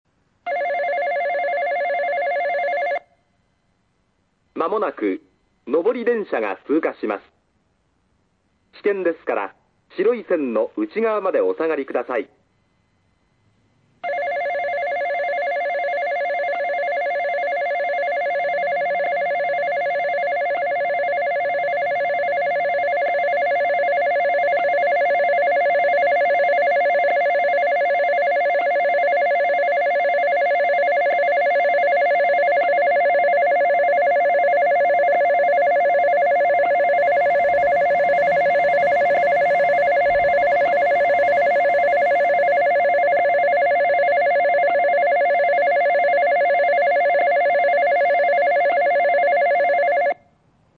●スピーカー：ソノコラム・小
●音質：D
２番線 通過放送・上り（福岡天神方面）　（248KB/50秒）
男性声のみの行先を言わない簡易放送ですが、「上り」「下り」の２パターンあります。また通過放送には連ベルが連動されます。